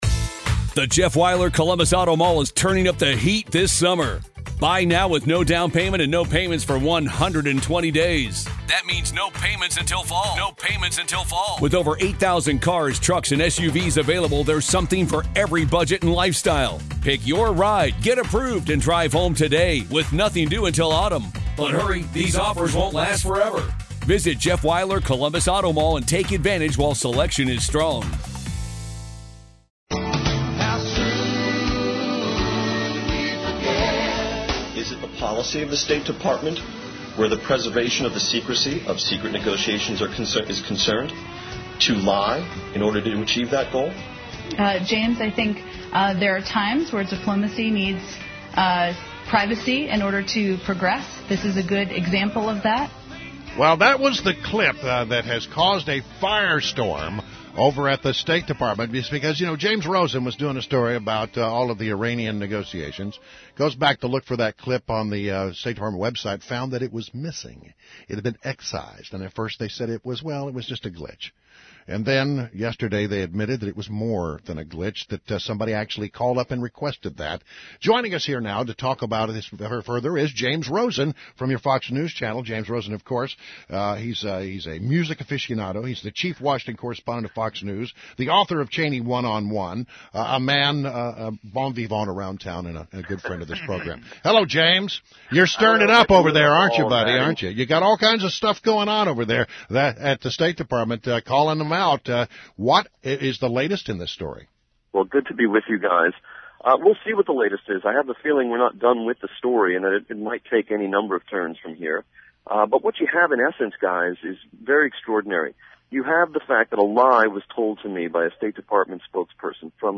WMAL Interview - JAMES ROSEN - 06-02.16